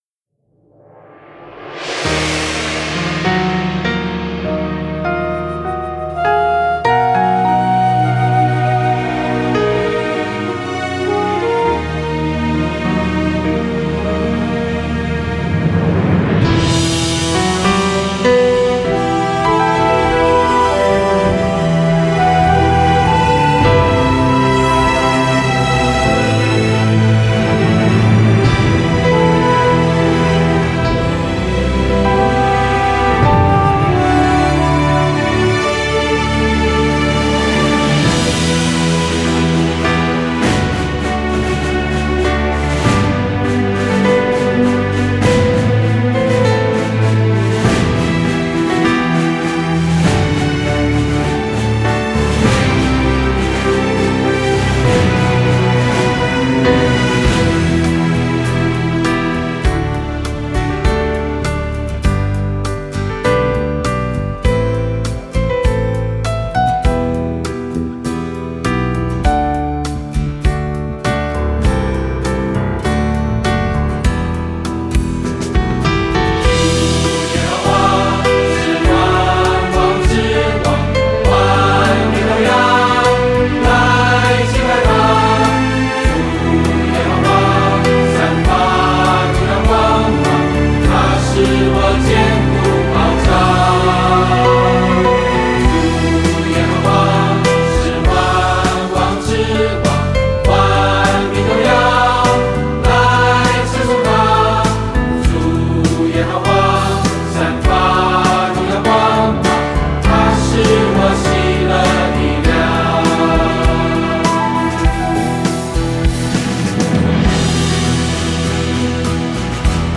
mp3 伴唱音樂